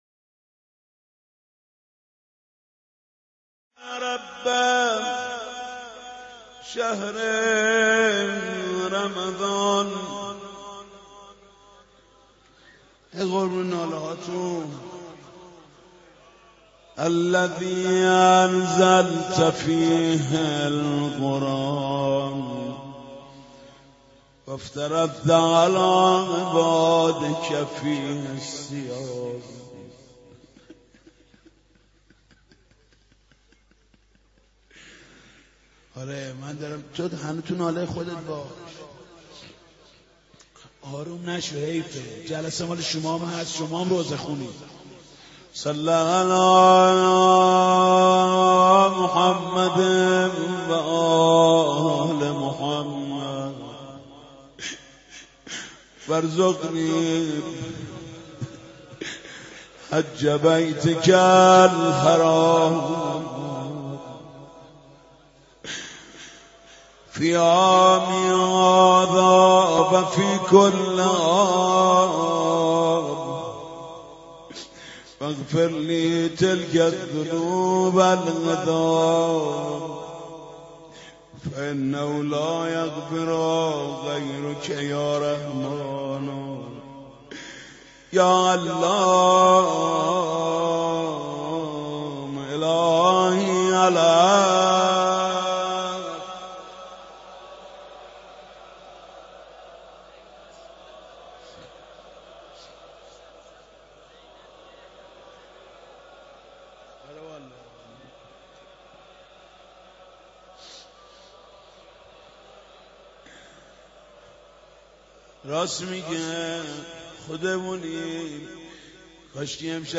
در این بخش مدیحه سرایی و نجوای حاج محمود کریمی در شب نوزدهم ماه مبارک رمضان را دریافت خواهید کرد.
برچسب ها: شب نوزدهم ، ماه رمضان ، محمود کریمی ، شب قدر ، مدیحه ، مداحی